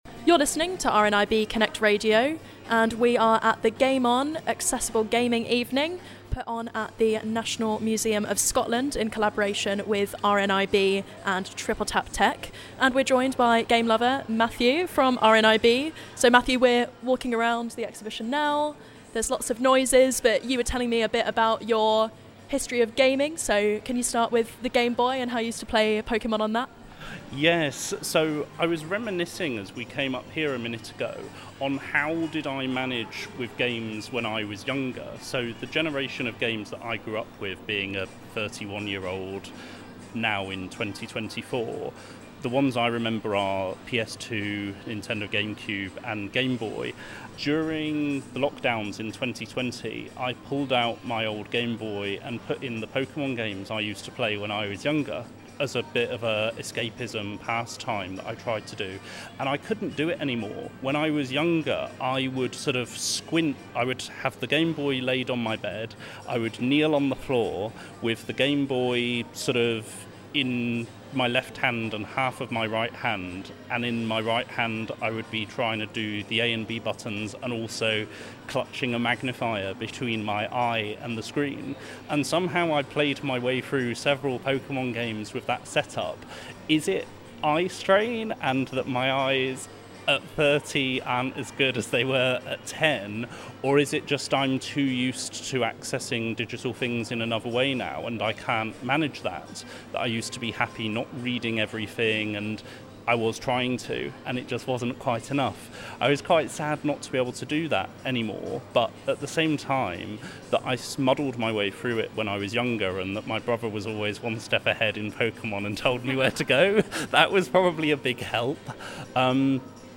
Game on is an immersive exhibition exploring the history of video games at The National Museum of Scotland.